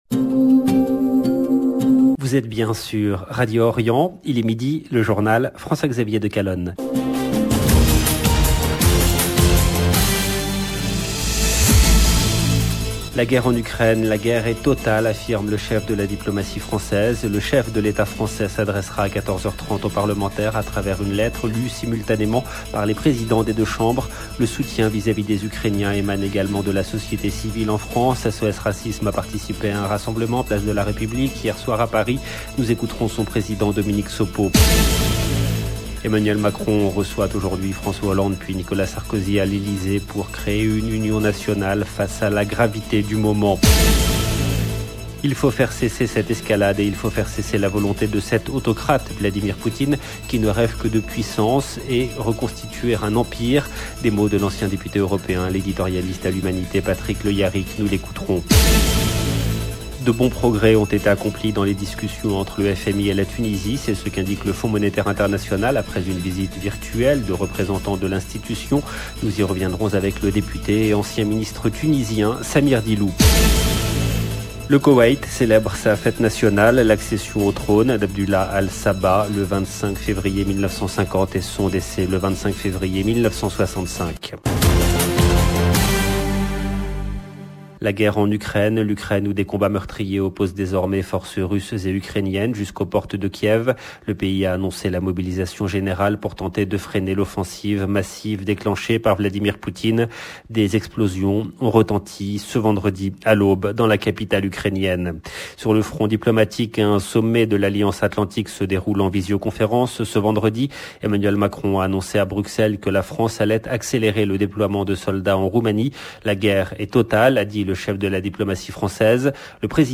LE JOURNAL DE MIDI EN LANGUE FRANCAISE DU 25/02/22
Nous y reviendrons avec le député et ancien ministre Samir Dilou.